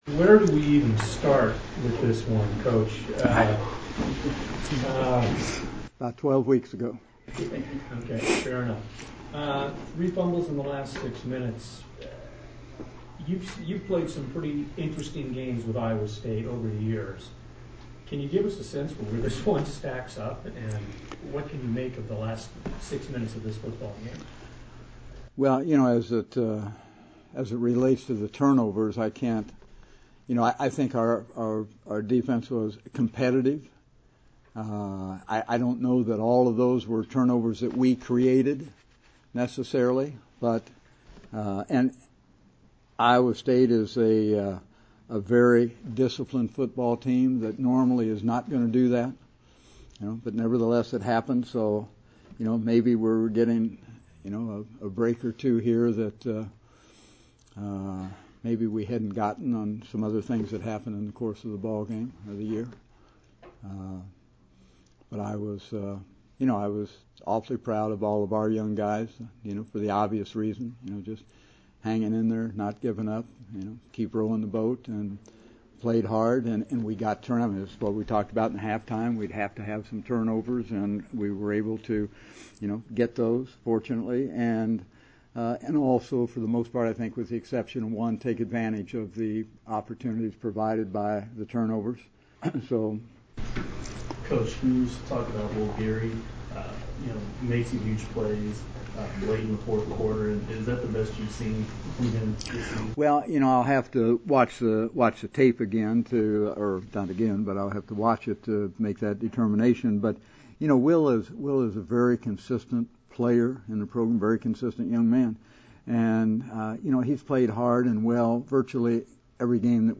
Coach Bill Snyder Postgame